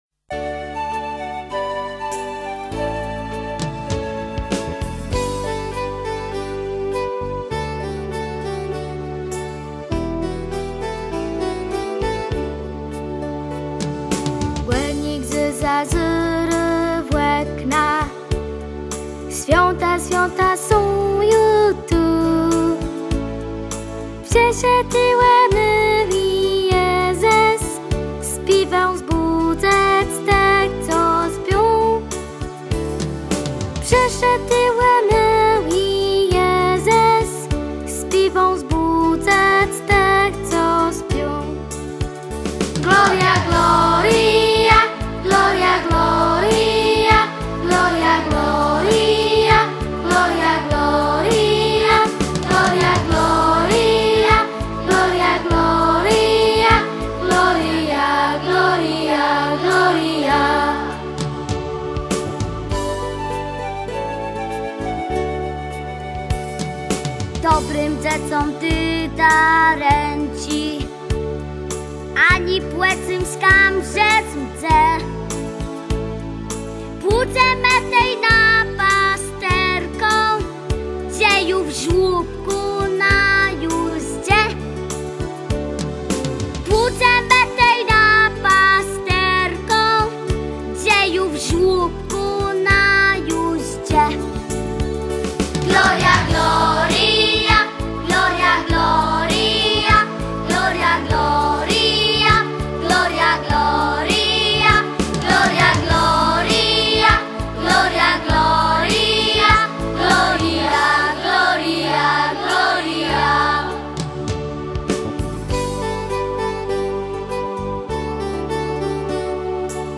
Kolęda dla dzieci.
Choranka-o-Jezesku-Borkowo-I.mp3